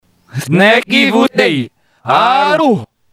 hovoria odzadu